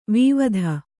♪ vīvadha